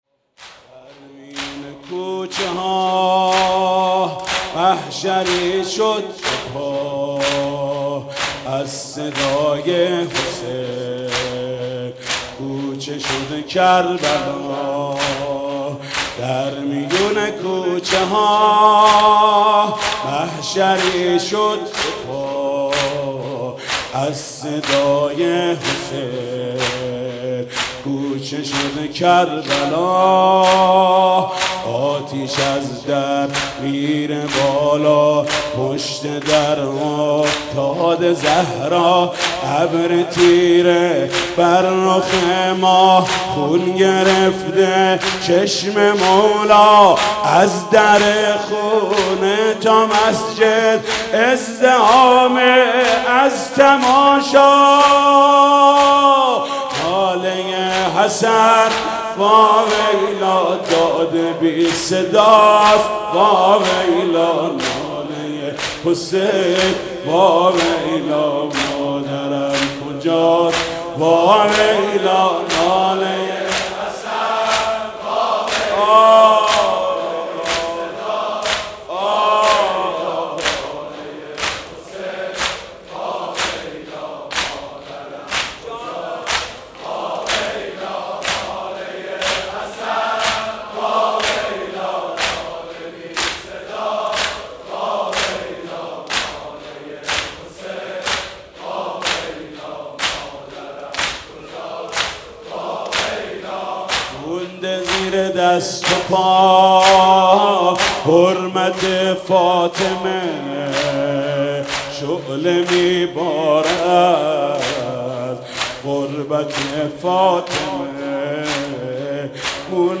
«فاطمیه 1389» زمینه: در میون کوچه ها محشری شد